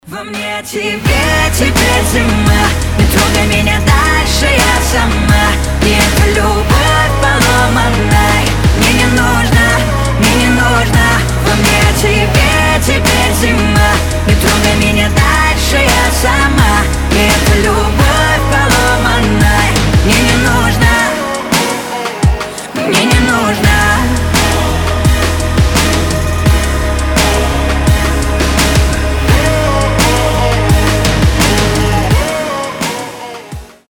• Качество: 320, Stereo
поп
женский голос